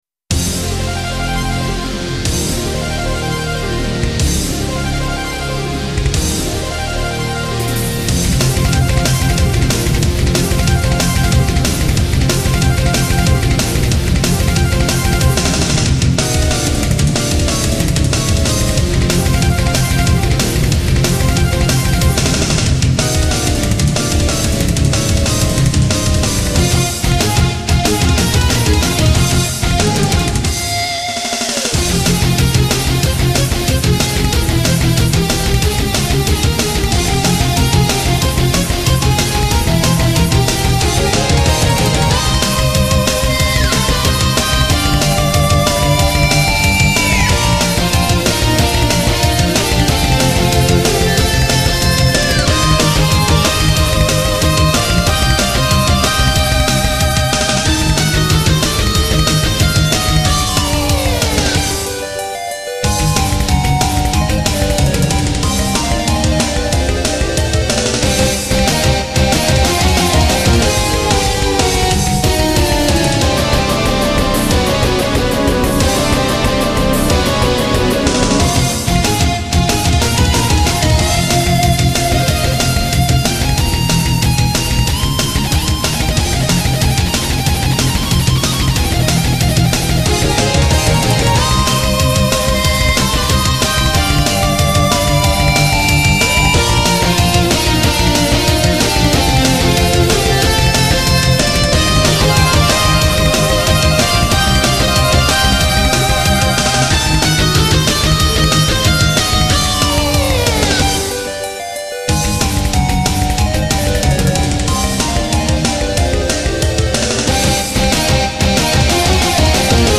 YAMAHA MU2000を使ってゲームBGMのメタルアレンジをしています
mp3 軽くておまぬけなアレンジ